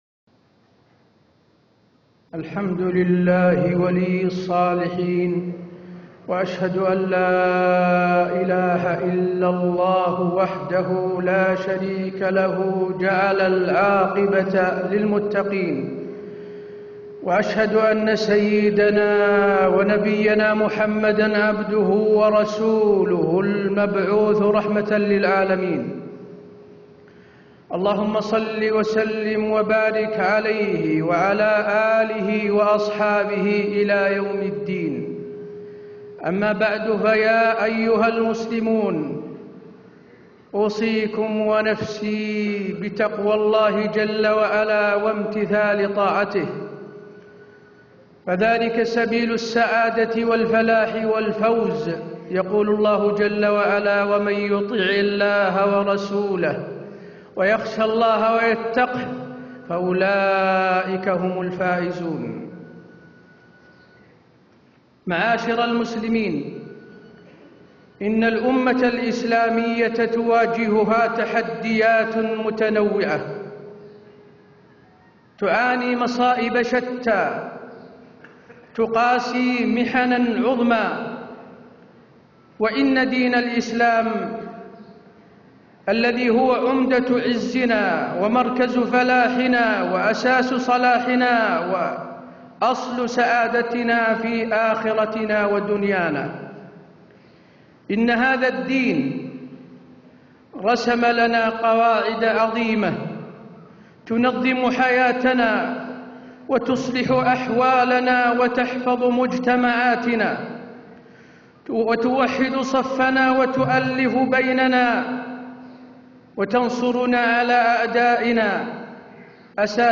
خطبة الجمعة 29 رجب 1437هـ > خطب الحرم النبوي عام 1437 🕌 > خطب الحرم النبوي 🕌 > المزيد - تلاوات الحرمين